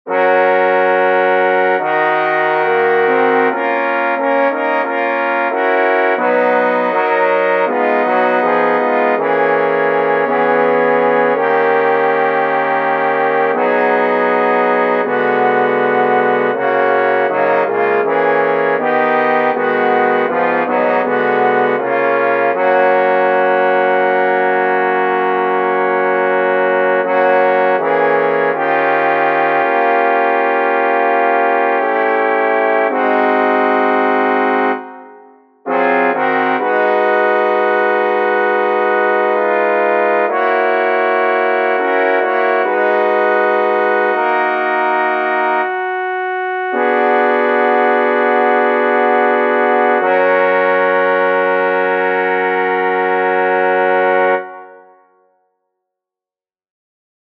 Key written in: F# Major
How many parts: 4
Type: Barbershop
All Parts mix: